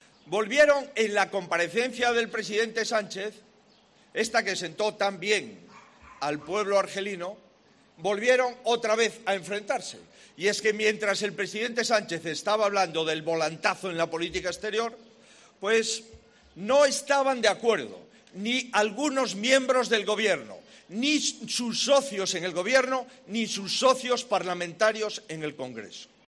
"No importa el asunto de relevancia o la urgencia del asunto para acreditar que este Gobierno está roto, y que está dividido y que es especialista en discutir entre sí y no en traer soluciones para todos", ha dicho el líder el PP durante su intervención en un mitin en Cádiz.